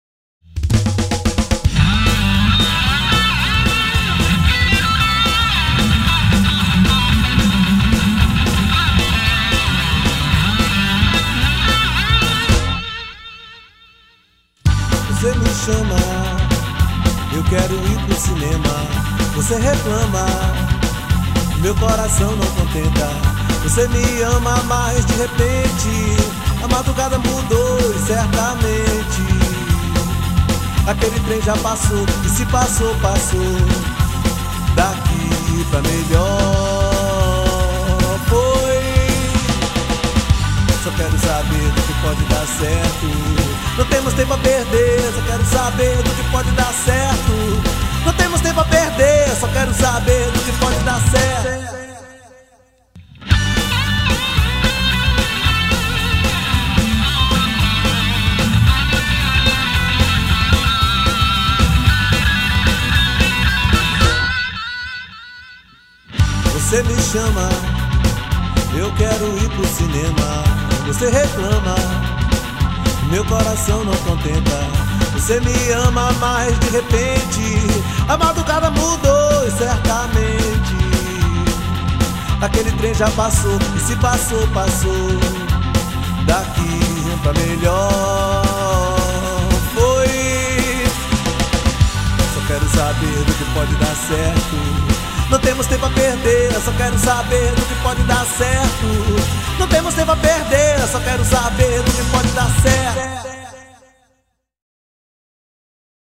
2611   01:45:00   Faixa: 3    Reggae